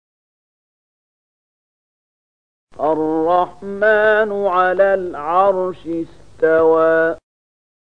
020005 Surat Thaahaa ayat 5 dengan bacaan murattal ayat oleh Syaikh Mahmud Khalilil Hushariy: